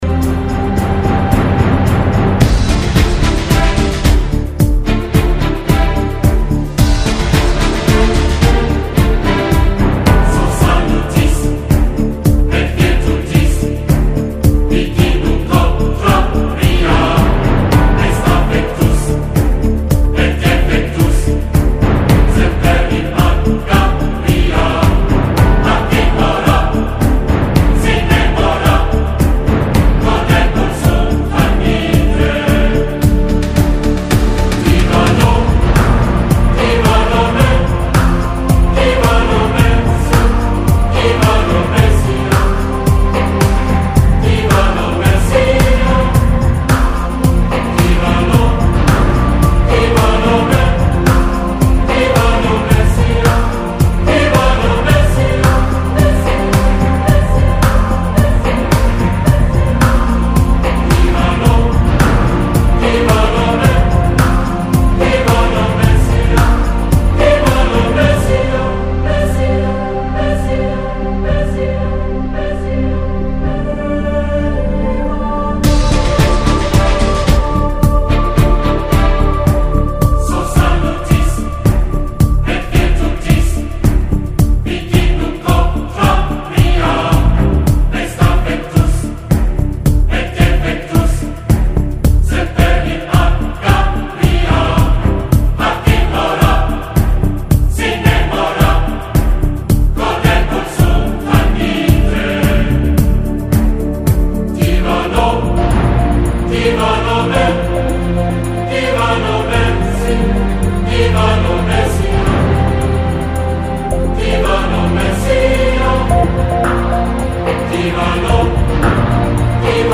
匠心独具的融合流行、摇滚及古典乐，经过截枝去叶后而产生简洁有力的音乐风格
大量运用交响乐
时而如摩西开海的壮阔，时而又像沙漠中拖动巨石的孤寂